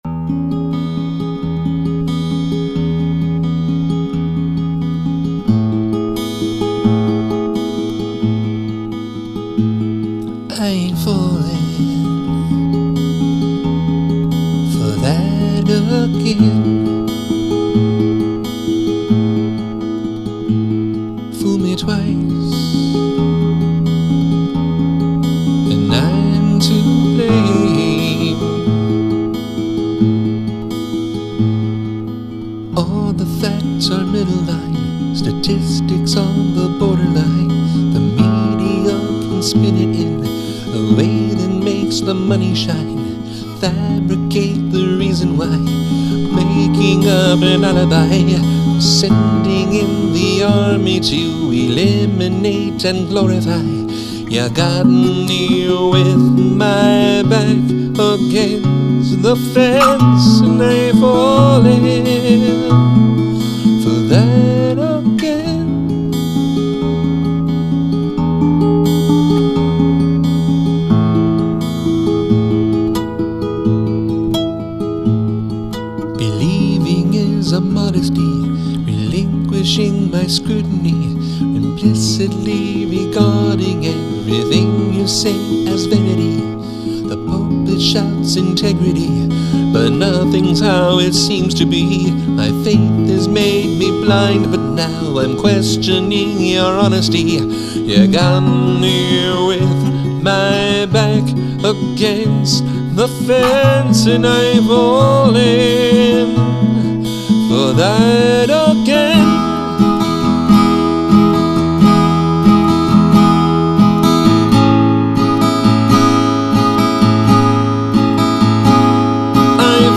tense acoustic singer/songwriter type song